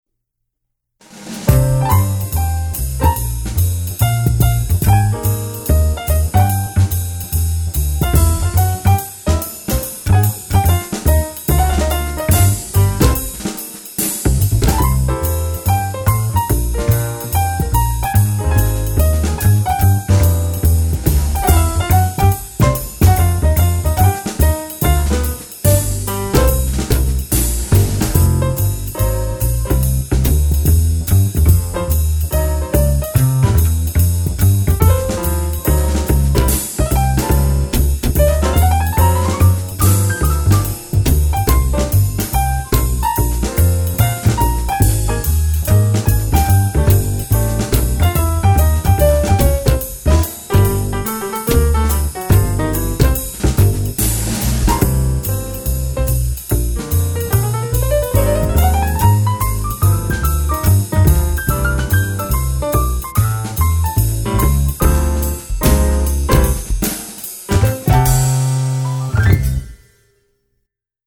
Jazz